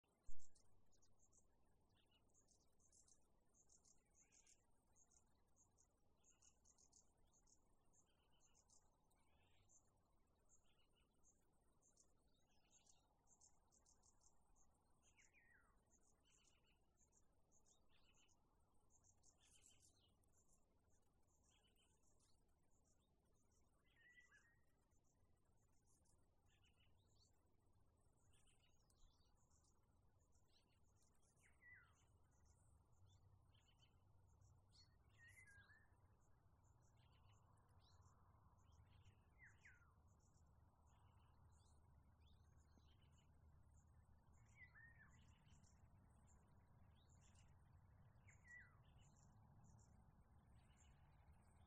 Bird Aves sp., Aves sp.
StatusVoice, calls heard
Notes/punkts aptuvens, kāda uztraukuma saucieni?, tā kā no pļavas vai krūmāja, fonā ar vālodzi